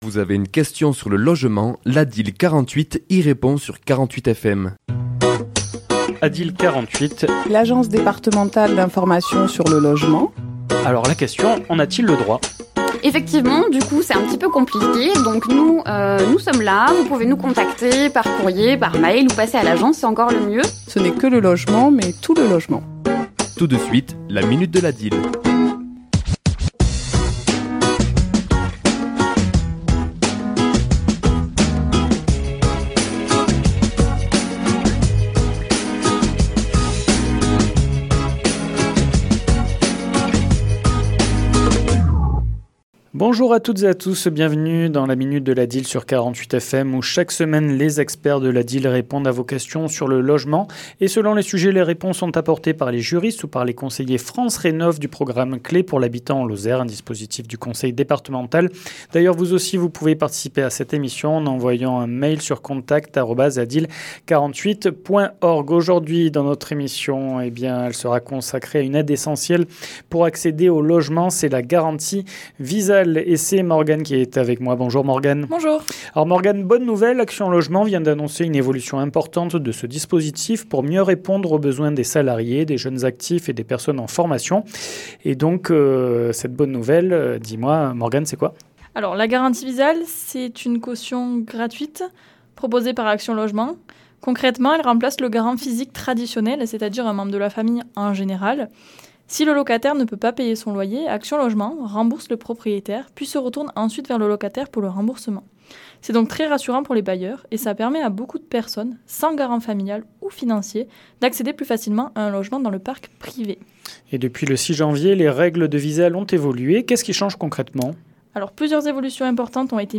Chronique diffusée le mardi 10 février à 11h et 17h10